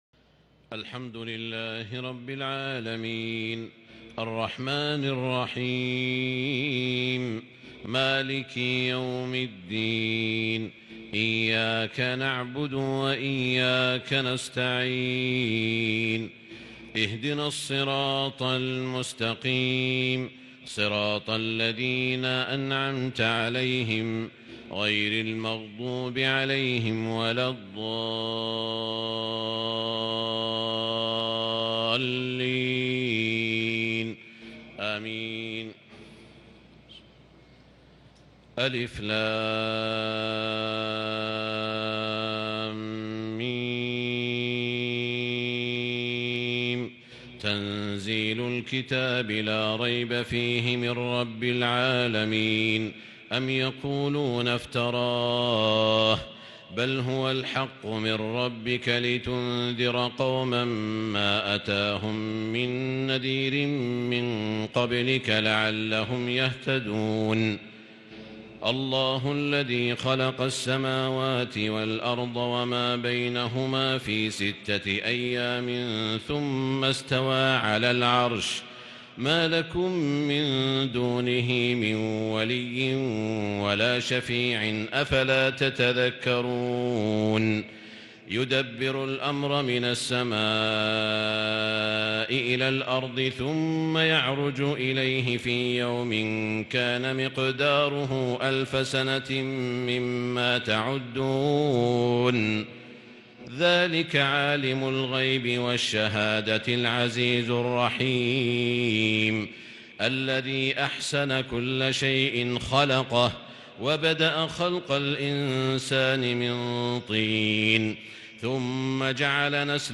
صلاة التهجّد ليلة 24 رمضان 1442هـ| سورة السجدة كاملة سورة الأحزاب (1-52 )| Tahajjud prayer The night of Ramadan 24 1442 | surah AsSajdah l and Al-Ahzab > تراويح الحرم المكي عام 1442 🕋 > التراويح - تلاوات الحرمين